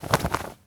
foley_cloth_light_fast_movement_02.wav